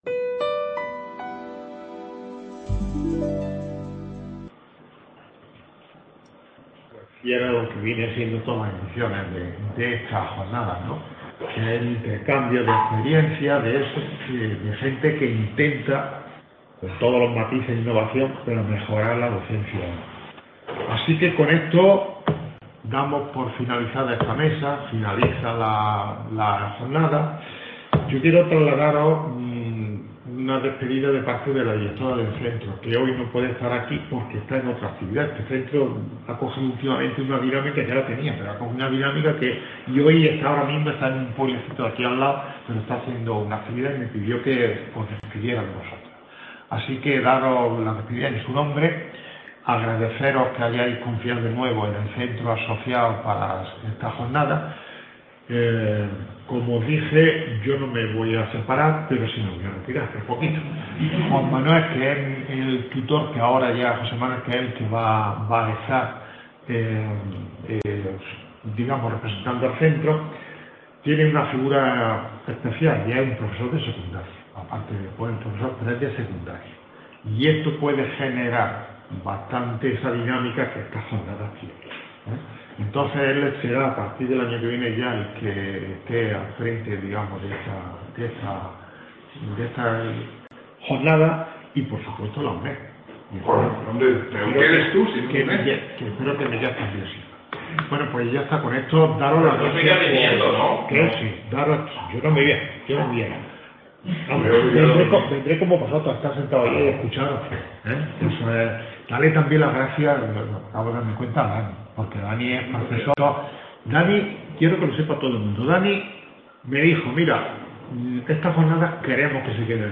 Clausura de las Jornadas.